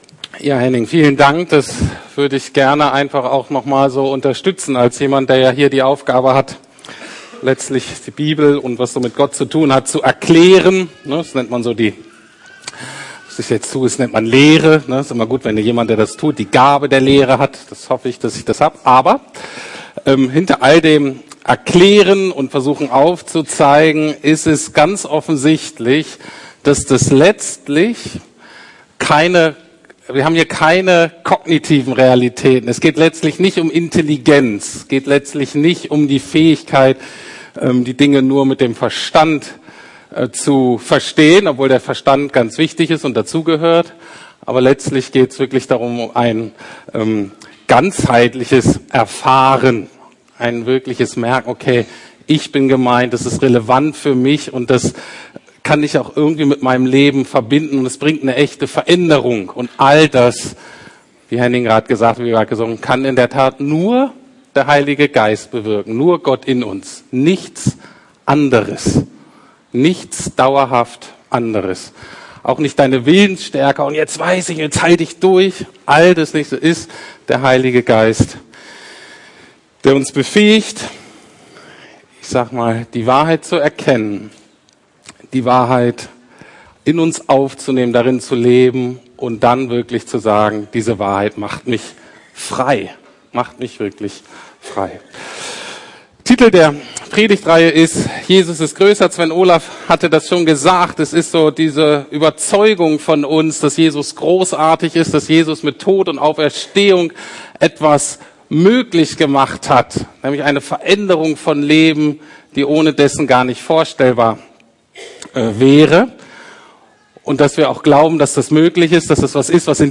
Gott ist größer als meine Scham ~ Predigten der LUKAS GEMEINDE Podcast